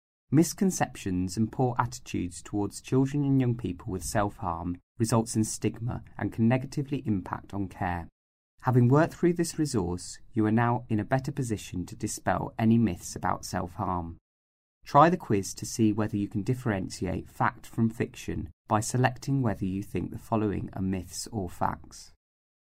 Narration audio (MP3) Narration audio (OGG) Contents Home What is this learning resource for?